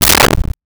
Cabinet Door Close 01
Cabinet Door Close 01.wav